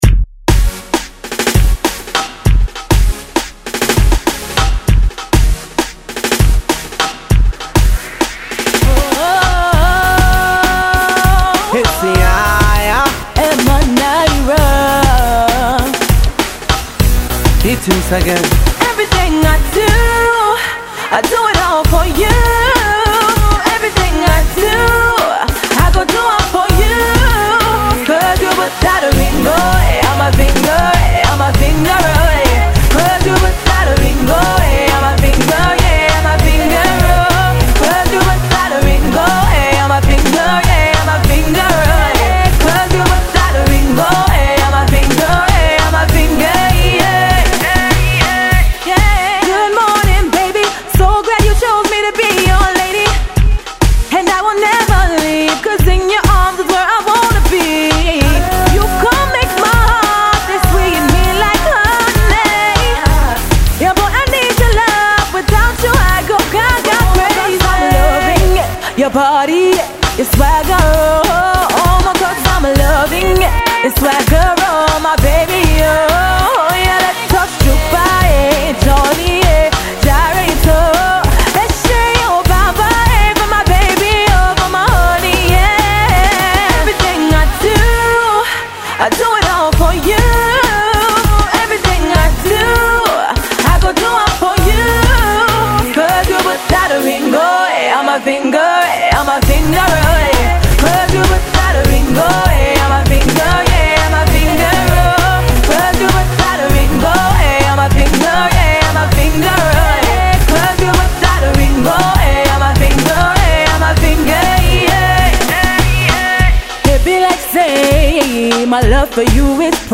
two blazing love ballads laced with hefty beats